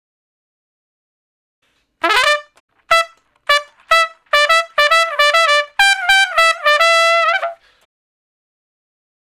084 Trump Shuffle (E) 08.wav